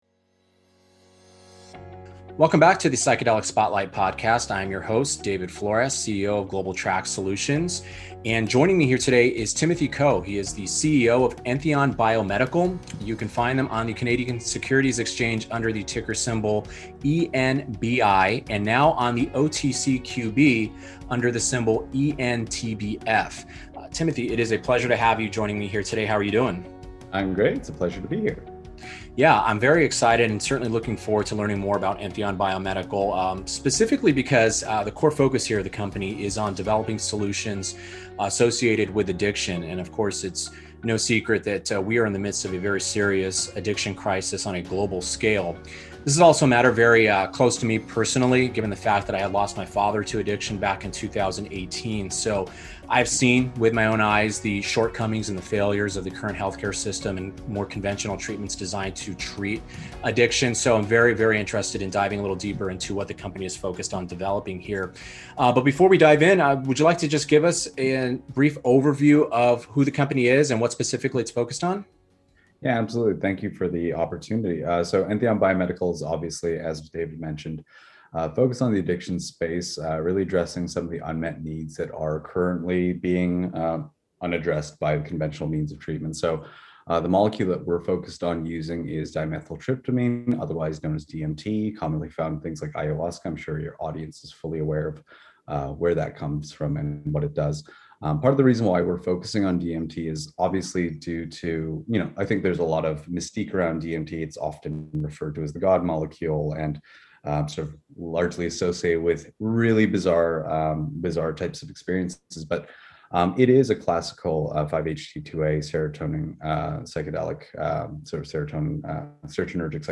This interview was recorded on July 29, 2021.